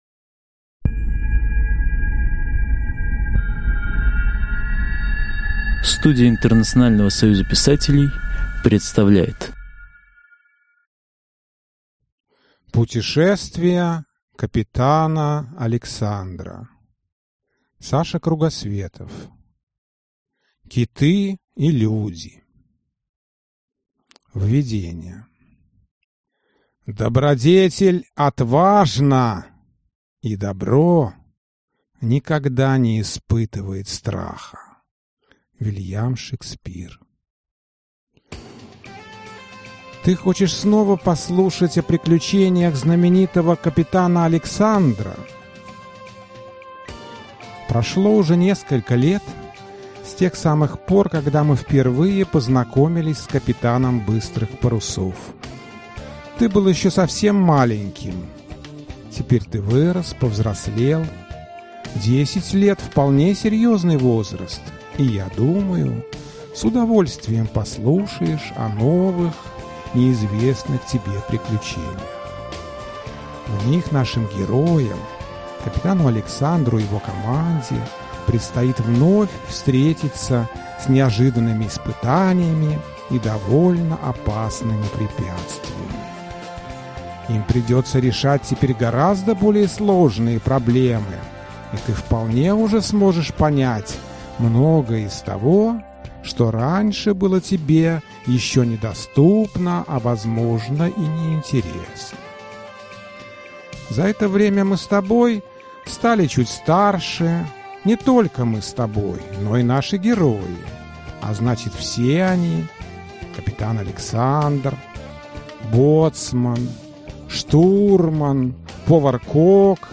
Аудиокнига Киты и люди | Библиотека аудиокниг